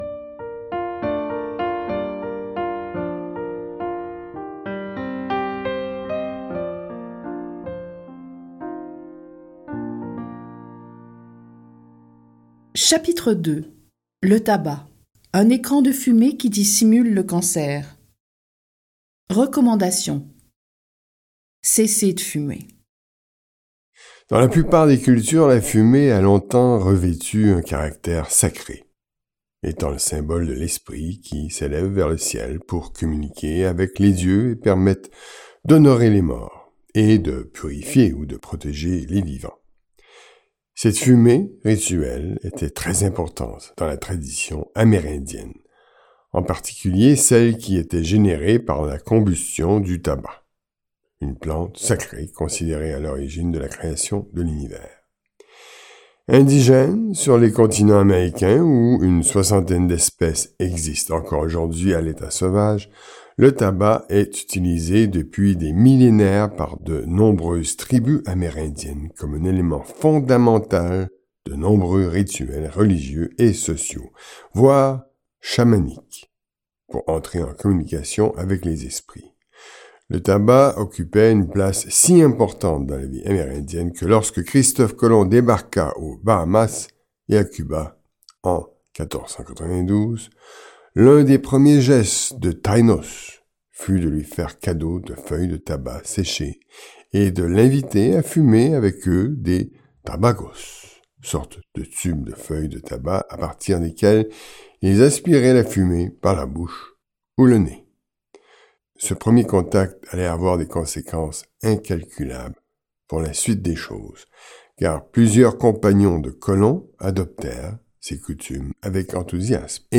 Ce livre audio fournit les informations nécessaires à ceux qui désirent rompre avec le défaitisme face au cancer et prendre leur destinée en main.